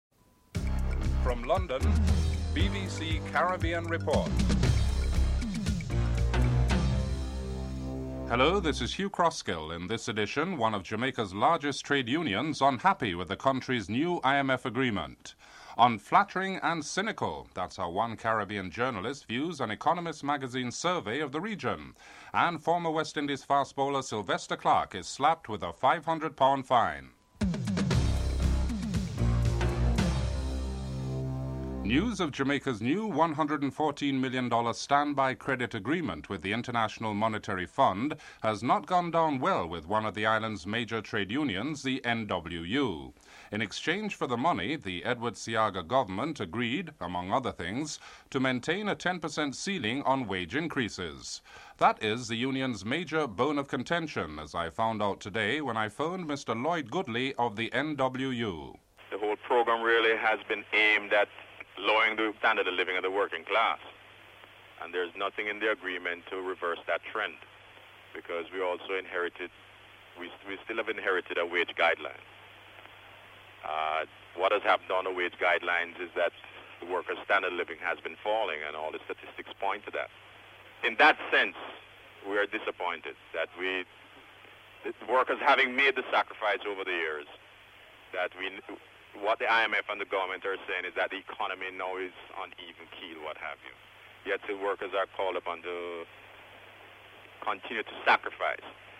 This segment features a portion of the verbal exchange that took place via telephone between the two men regarding the publication.